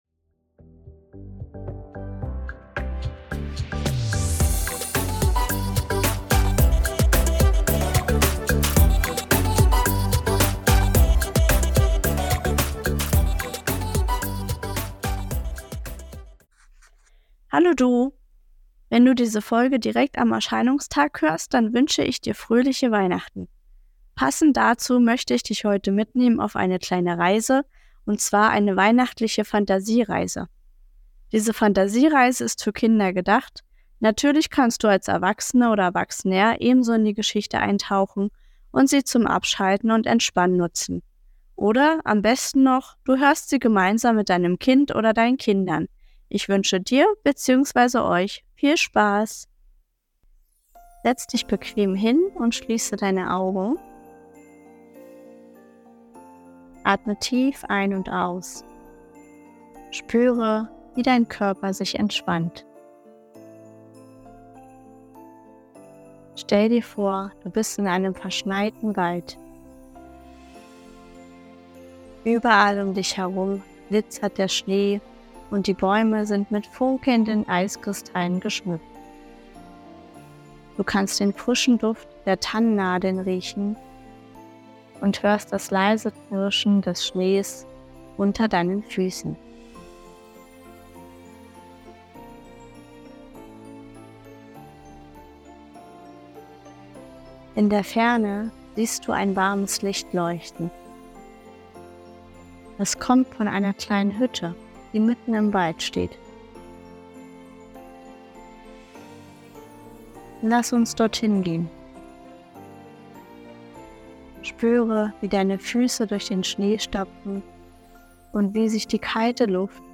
Heute gibt´s Entspannung auf die Ohren.
Ich nehme dich mit auf eine Fantasiereise.